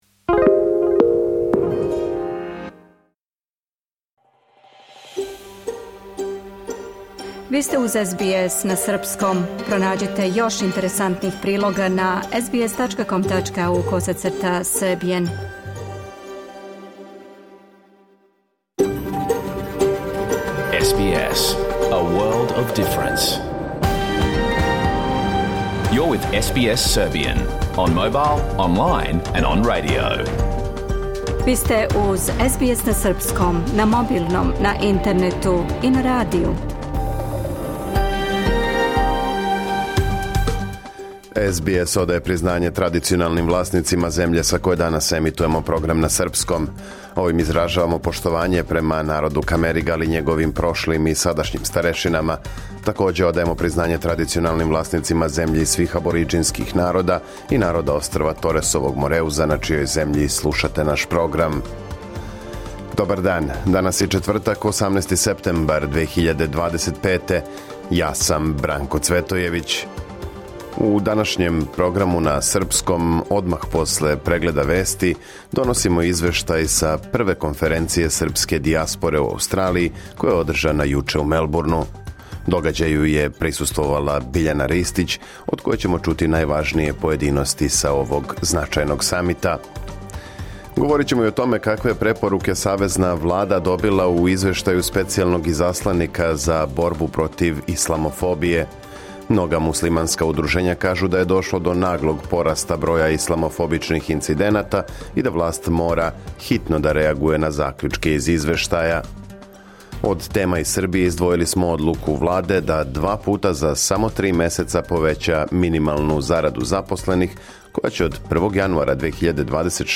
Програм емитован уживо 18. септембра 2025. године